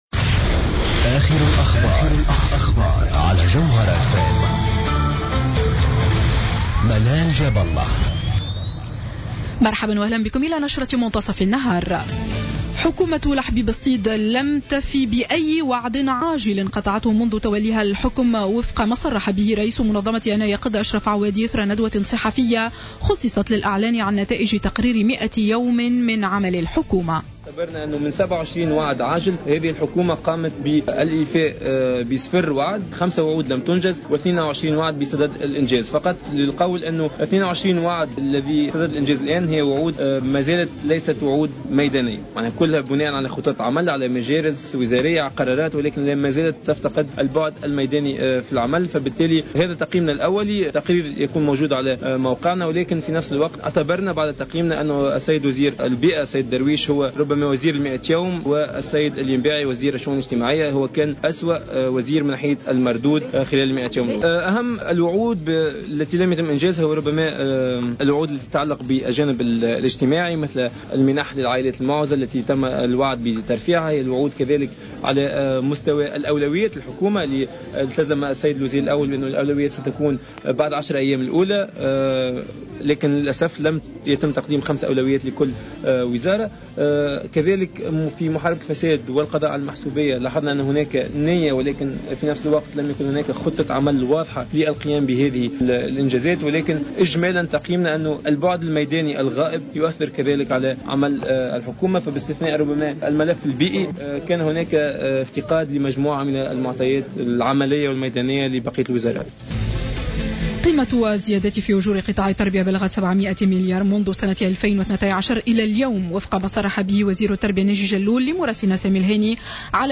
نشرة أخبار منتصف النهار ليوم السبت 16 ماي 2015